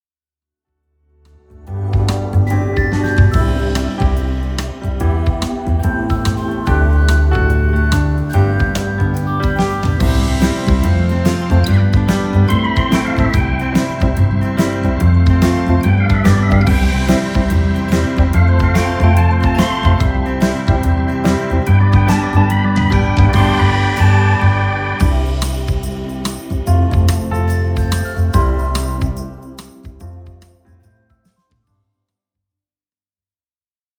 (Play-Back) (1.29 EUR)